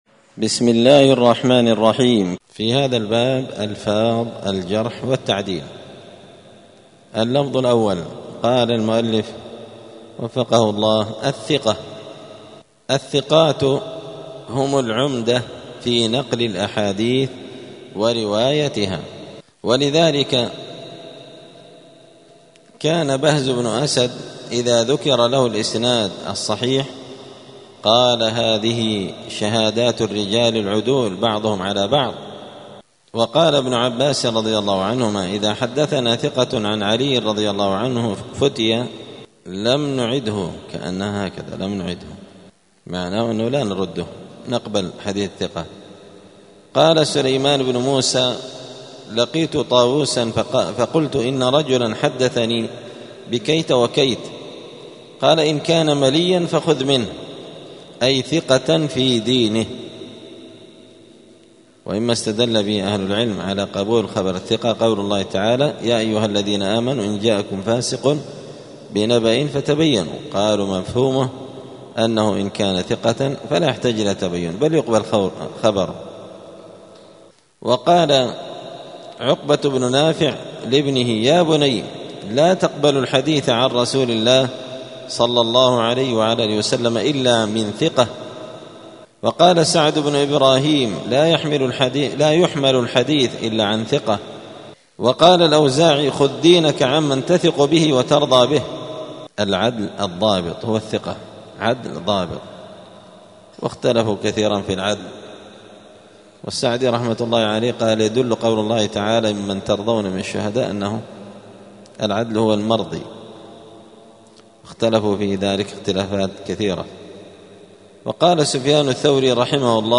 *الدرس الثاني بعد المائة (102) باب ألفاظ الجرح والتعديل {الثقة}*